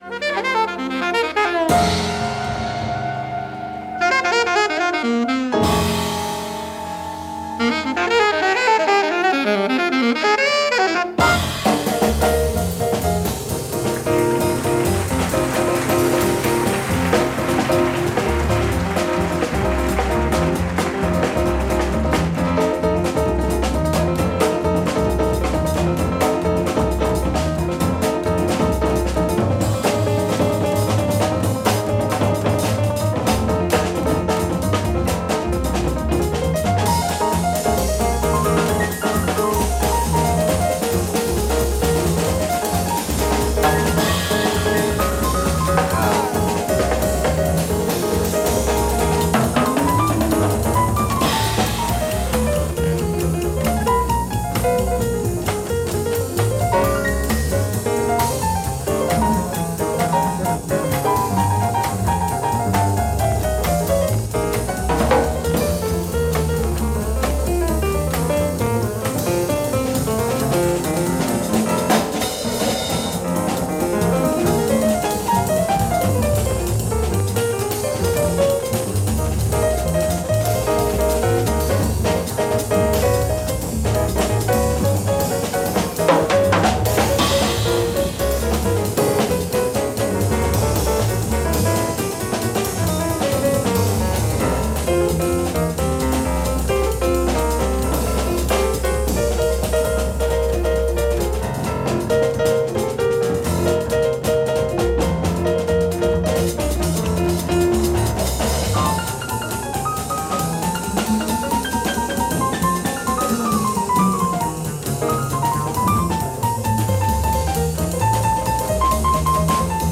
Straight ahead
live at Foyer B – Maison de la Radio, Paris